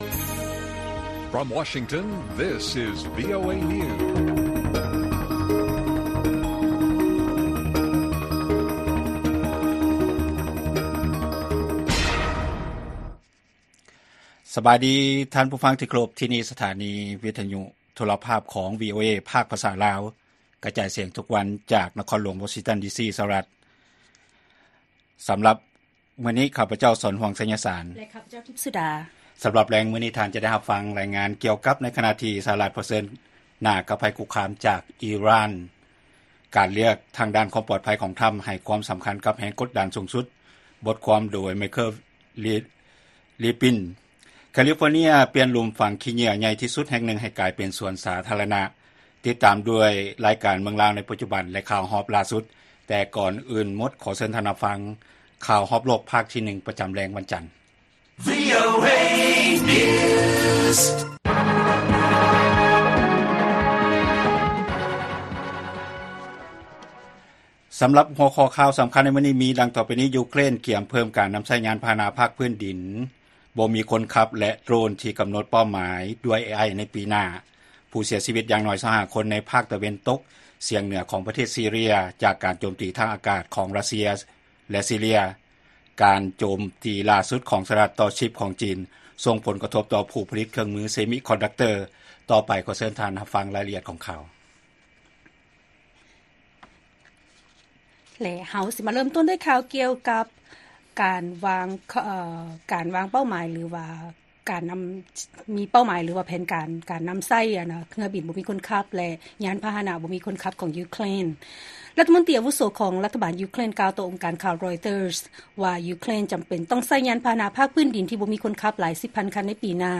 ວີໂອເອພາກພາສາລາວ ກະຈາຍສຽງທຸກໆວັນ, ຫົວຂໍ້ຂ່າວສໍາຄັນສໍາລັບແລງມື້ນີ້ ມີດັ່ງນີ້: ຢູເຄຣນ ກຽມເພີ້ມການນຳໃຊ້ຍານພາຫະນະພາກພື້ນດິນທີ່ບໍ່ມີຄົນຂັບ ແລະ ໂດຣນ ໃນປີໜ້າ, ມີຜູ້ເສຍຊີວິດຢ່າງໜ້ອຍ 25 ຄົນ ຈາກເຮືອບິນລົບ ຣັດເຊຍ ແລະ ຊີເຣຍ, ແລະ ການໂຈມຕີຫຼ້າສຸດຂອງ ສຫລ ຕໍ່ຊິບຂອງ ຈີນ ສົ່ງຜົນກະທົບຕໍ່ຜູ້ຜະລິດ semiconductor.